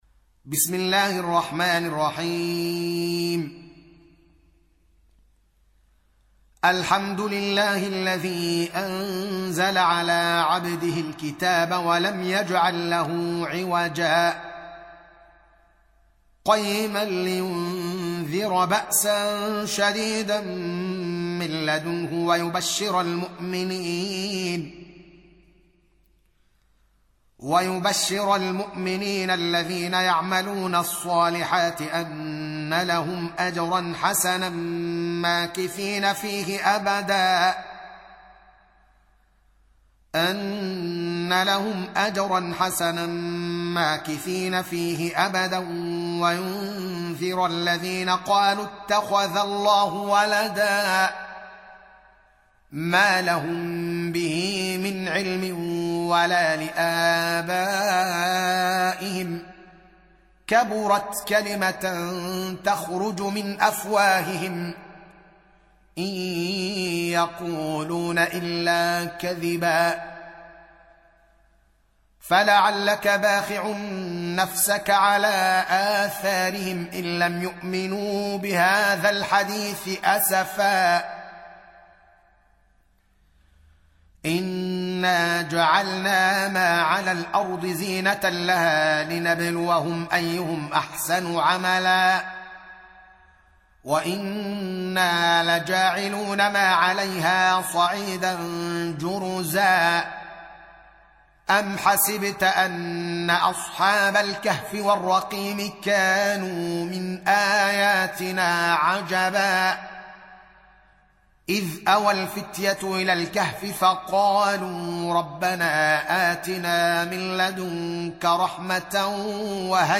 Surah Repeating تكرار السورة Download Surah حمّل السورة Reciting Murattalah Audio for 18. Surah Al-Kahf سورة الكهف N.B *Surah Includes Al-Basmalah Reciters Sequents تتابع التلاوات Reciters Repeats تكرار التلاوات